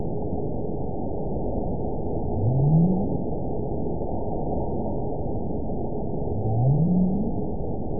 event 920792 date 04/09/24 time 05:14:54 GMT (2 weeks, 6 days ago) score 9.55 location TSS-AB01 detected by nrw target species NRW annotations +NRW Spectrogram: Frequency (kHz) vs. Time (s) audio not available .wav